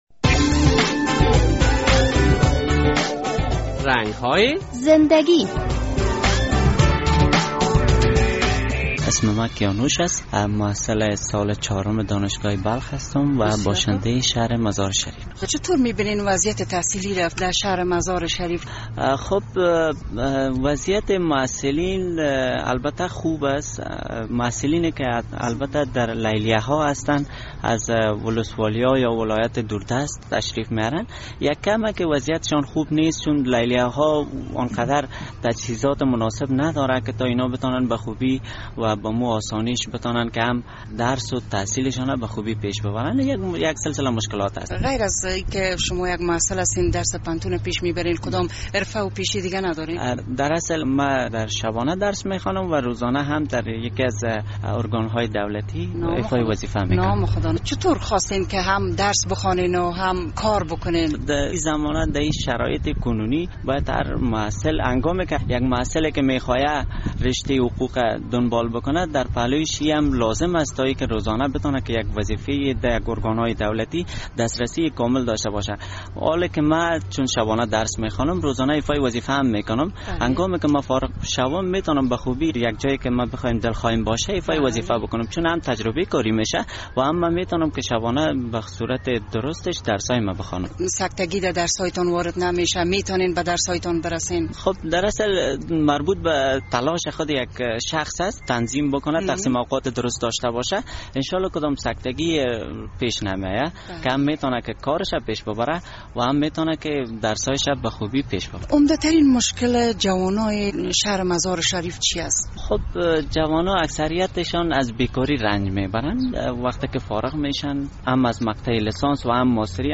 در این برنامۀ رنگ های زنده گی خبرنگار رادیو آزادی با یک تن از محصلین پوهنتون بلخ صحبت کرده است...